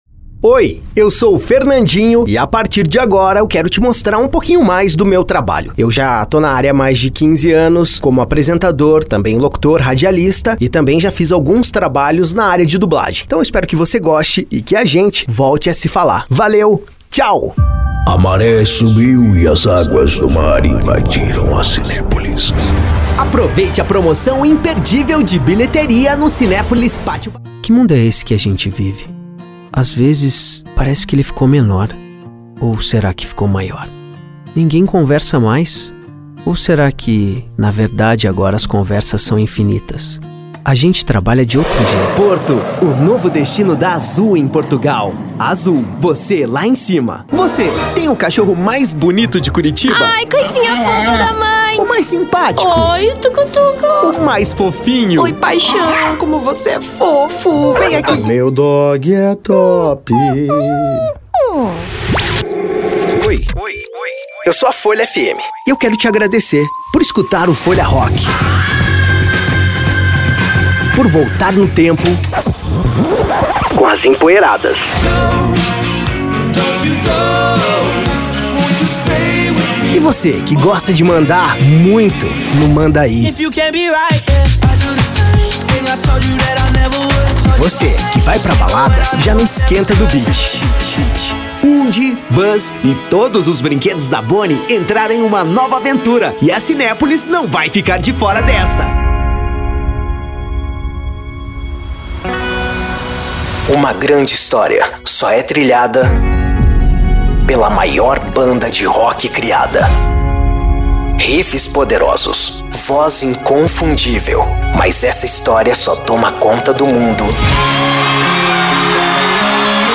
Spot Comercial
Vinhetas
Padrão
Animada
Caricata